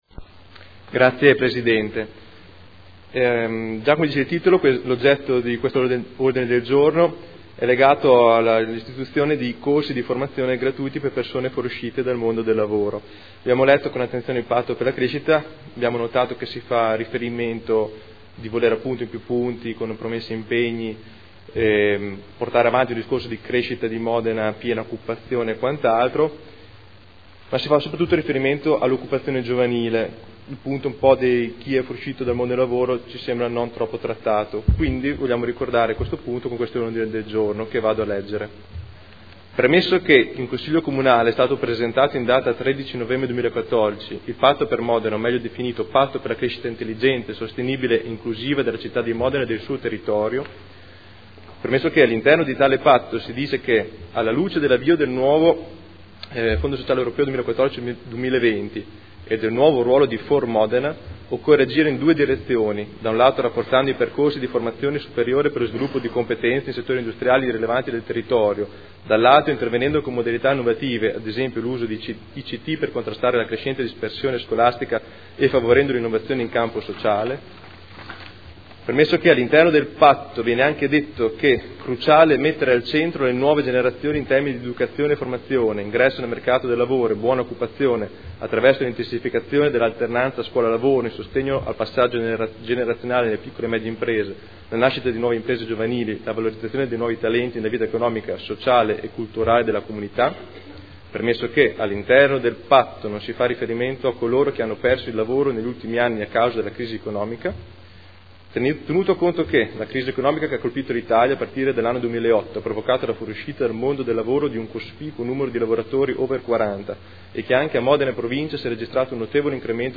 Seduta del 20 novembre 2014